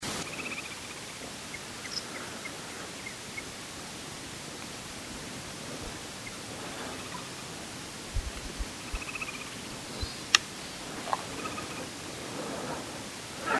Carpintero Bataraz Chico (Veniliornis mixtus)
Nombre en inglés: Checkered Woodpecker
Localidad o área protegida: Reserva de Biósfera Ñacuñán
Condición: Silvestre
Certeza: Vocalización Grabada
Carpintero-Bataraz-Chico.mp3